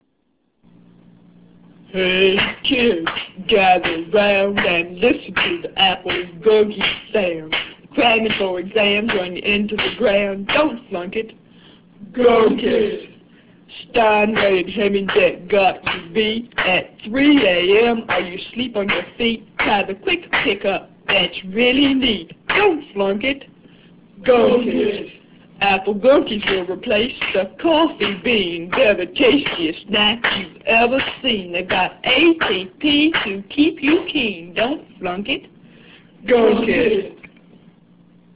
I decided it would be fun to run fake humorous commercials instead.